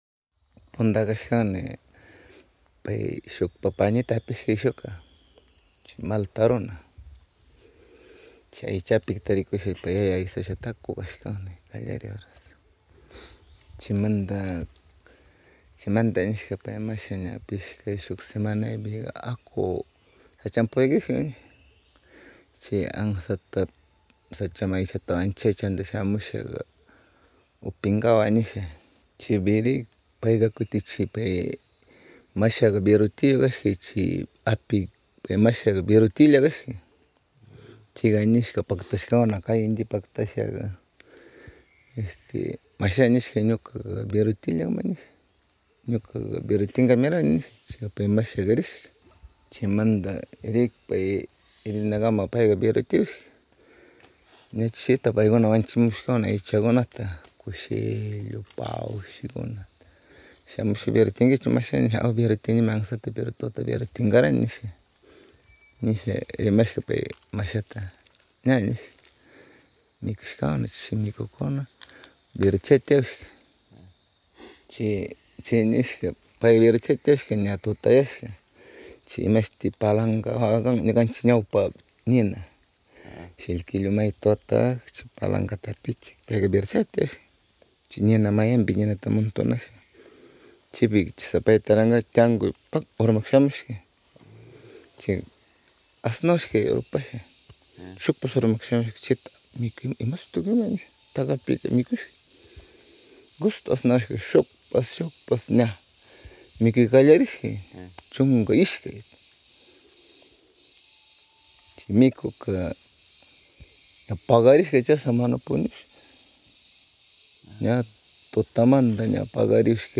cuento CABEZA DE VIENTO - Saparas
En la narración
bajo la sombra de un ceibo gigante.